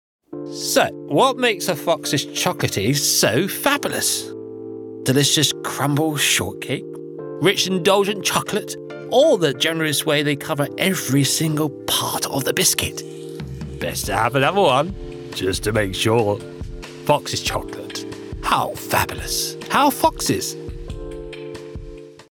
Démo commerciale
Mon accent est celui de l'estuaire, mais la plupart me connaissent comme un gars de l'Essex.
Baryton